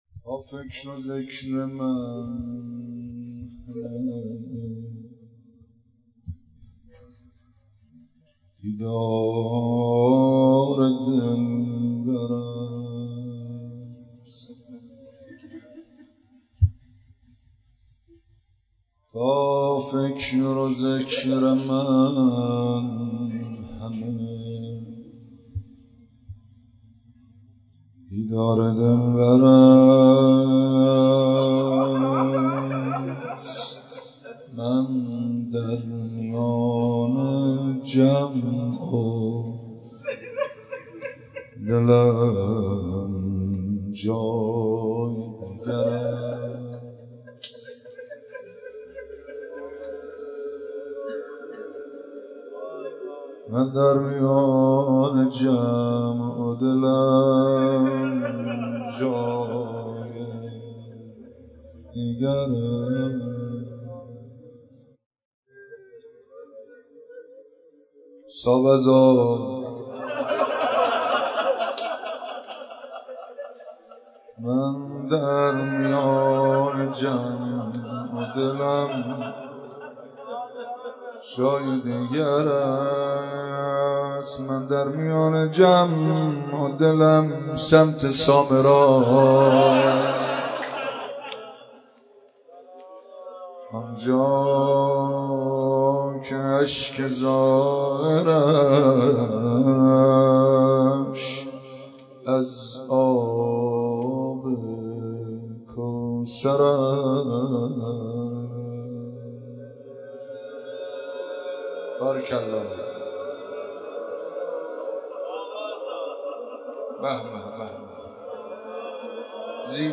دانلود مداحی ذکر من یا زهراست - دانلود ریمیکس و آهنگ جدید